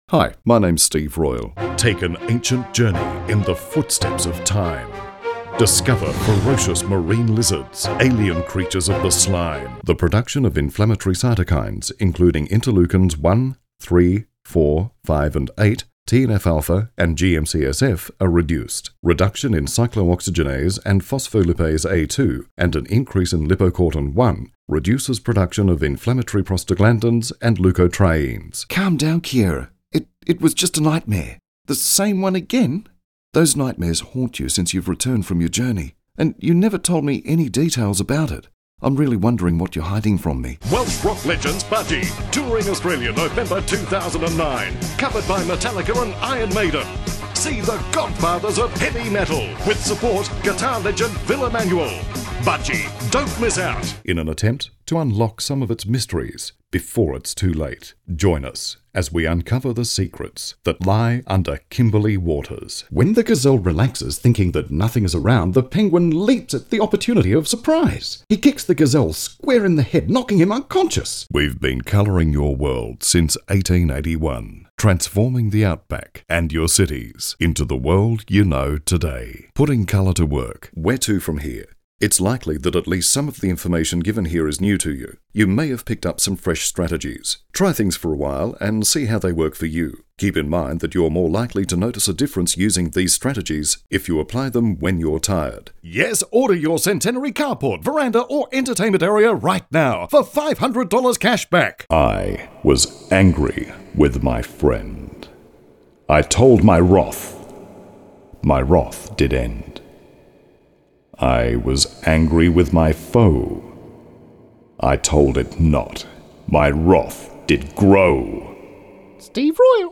Strong, crisp,versatile, convincing, authoritative, cheeky voice artist
englisch (australisch)
Sprechprobe: Sonstiges (Muttersprache):